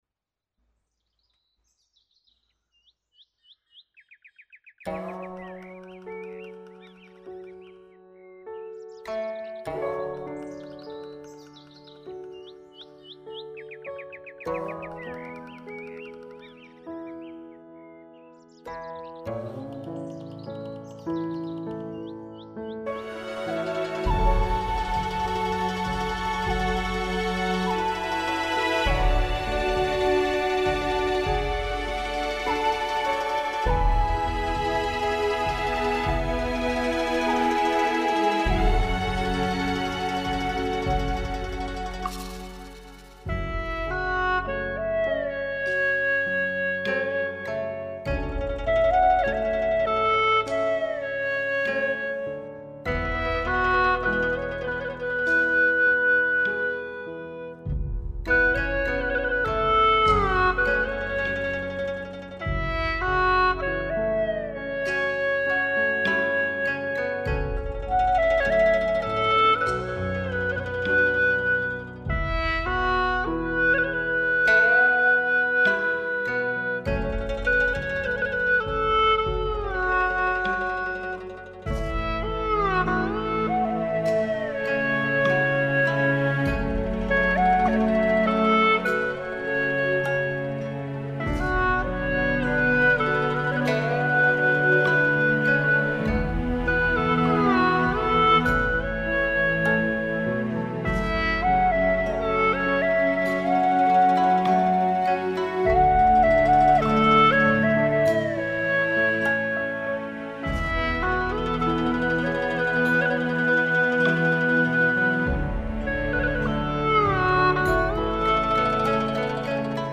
曲类 : 独奏